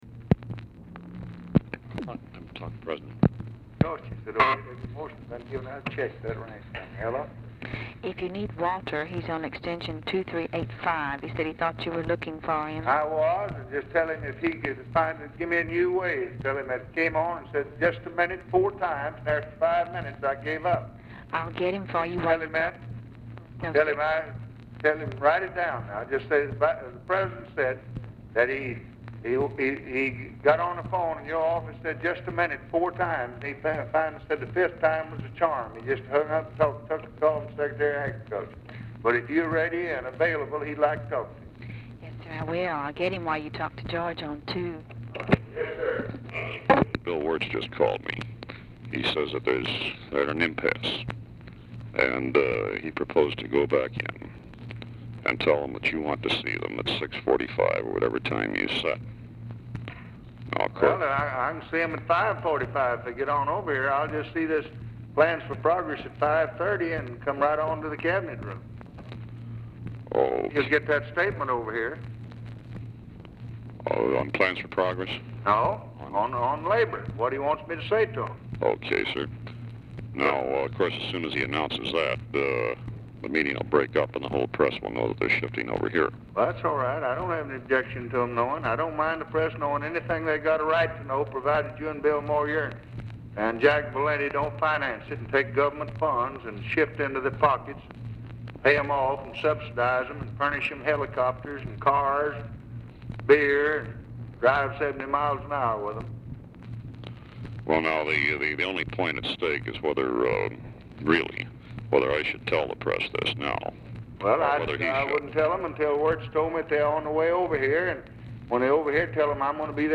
Telephone conversation # 2955, sound recording, LBJ and GEORGE REEDY, 4/9/1964, 5:10PM | Discover LBJ
Format Dictation belt
Location Of Speaker 1 Oval Office or unknown location